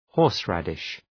Προφορά
{‘hɔ:rs,rædıʃ}